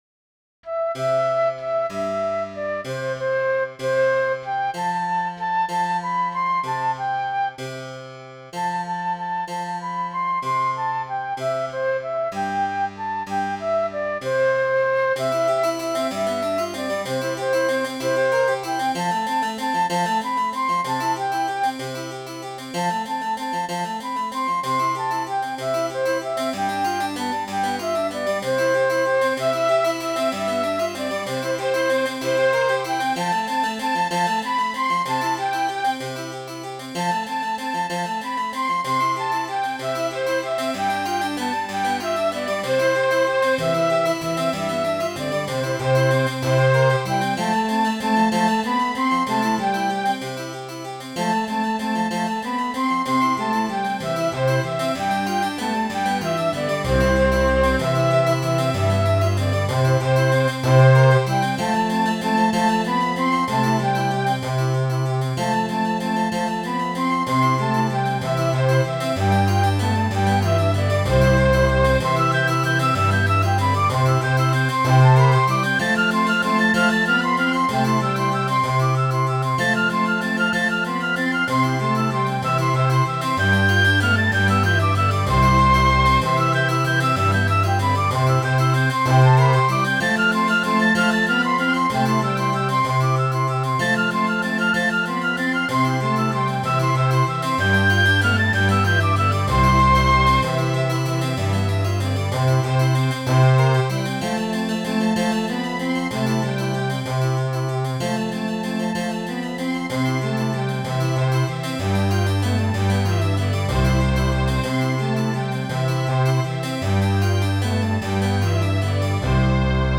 Midi File, Lyrics and Information to The Willow Tree